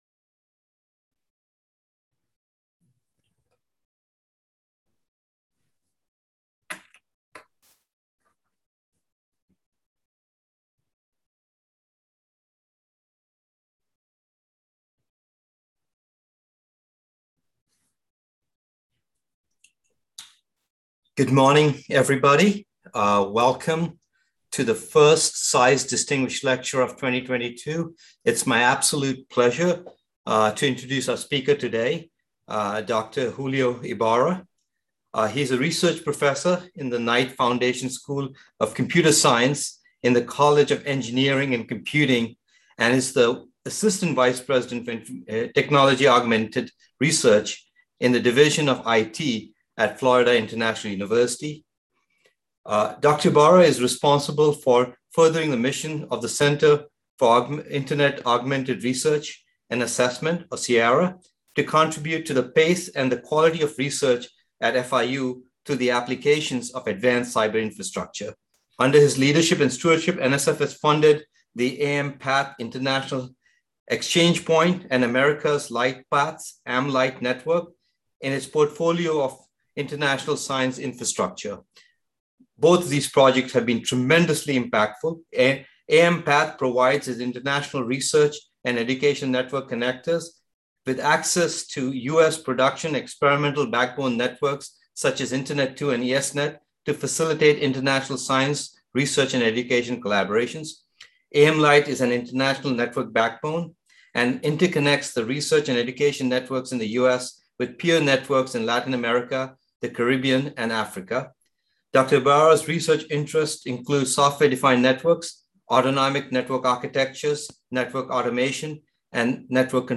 CISE Distinguished Lecture Series